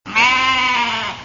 Pianeta Gratis - Audio - Animali
pecora_sheep08.wav